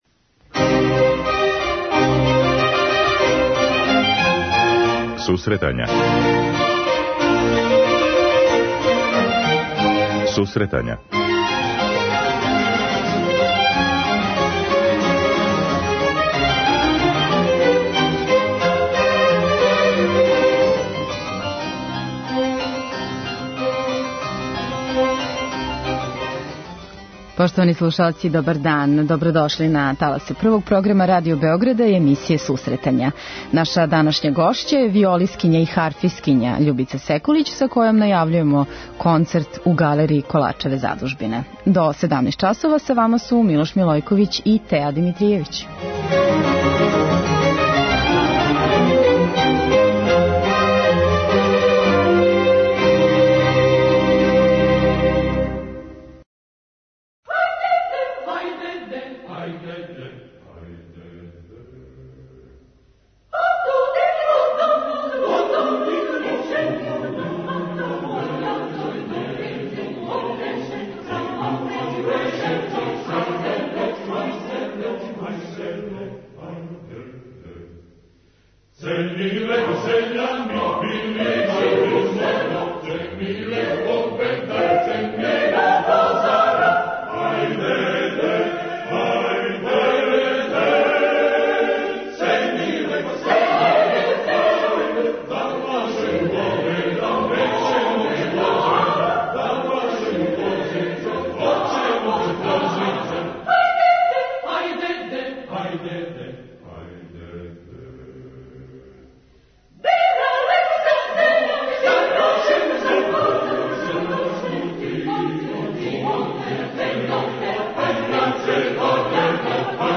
преузми : 10.77 MB Сусретања Autor: Музичка редакција Емисија за оне који воле уметничку музику.